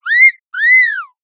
口笛-01 着信音